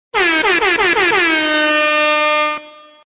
MLG Horns Sound Effect